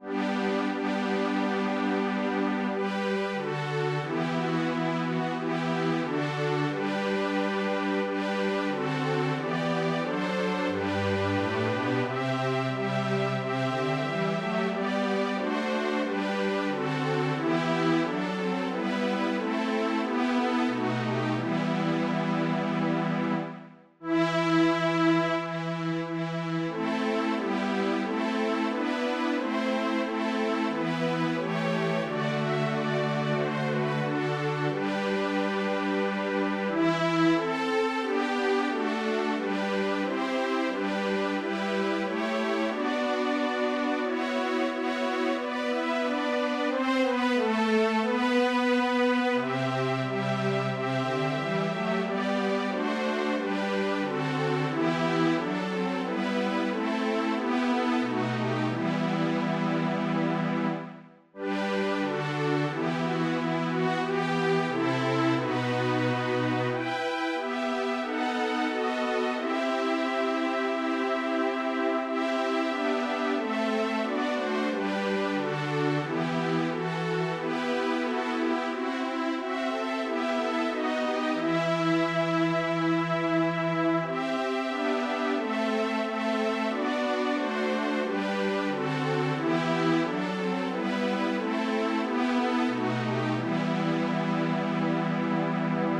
Трисвятое румынского напева для смешанного хора.